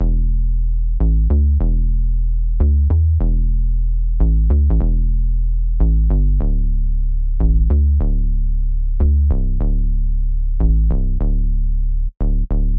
Tag: 150 bpm Ambient Loops Bass Loops 2.15 MB wav Key : Unknown